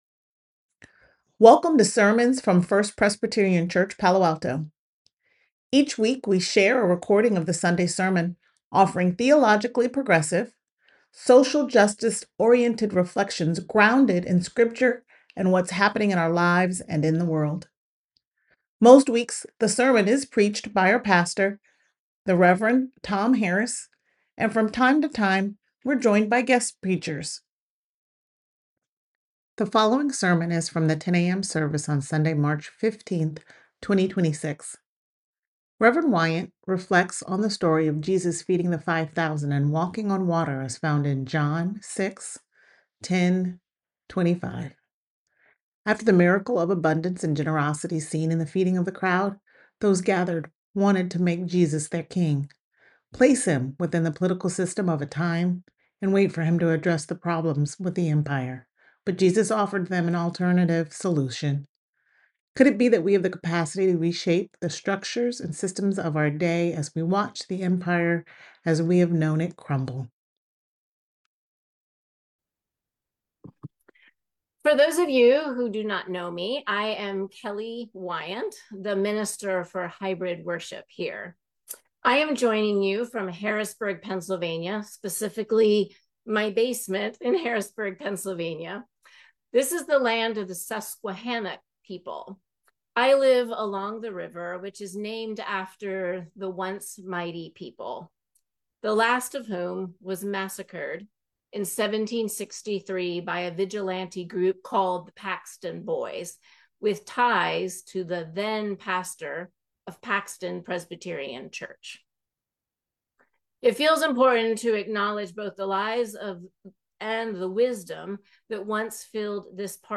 The following sermon is from the 10 a.m. service on Sunday, March 15th, 2026.
Sermon-31526a.mp3